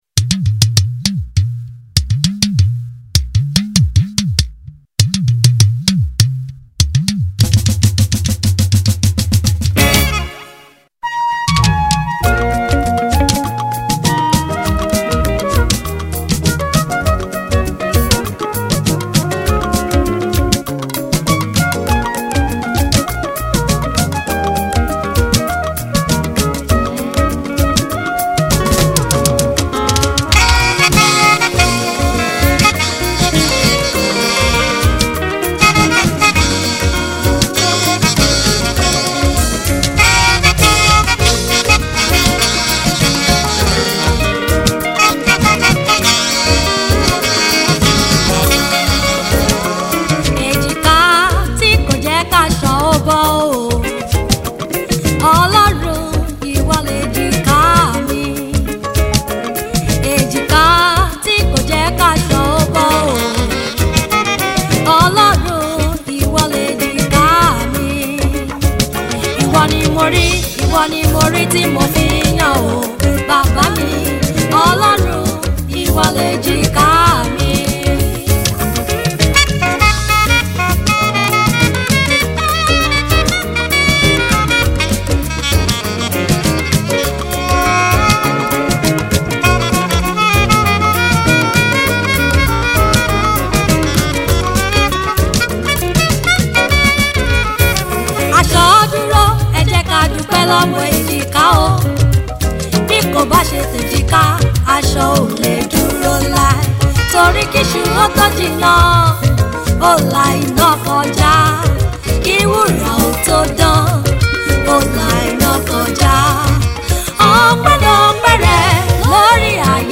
gospel juju